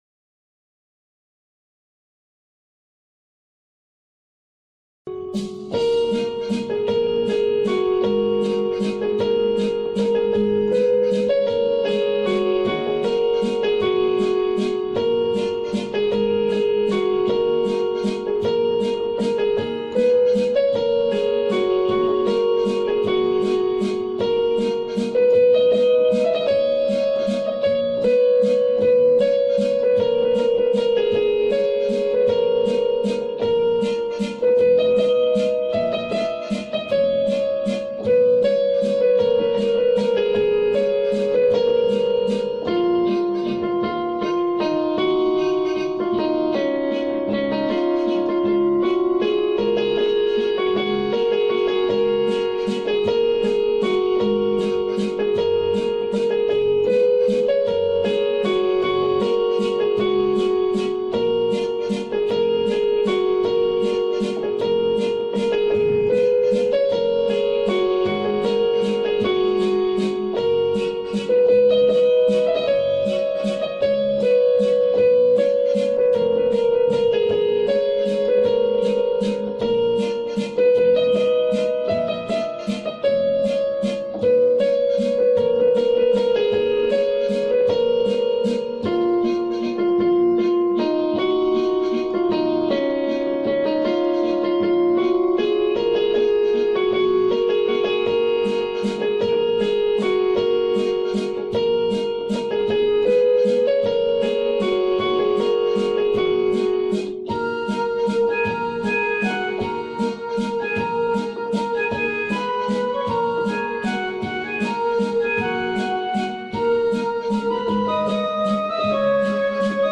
INSTRUMENTAL Section